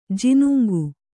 ♪ jinuŋgu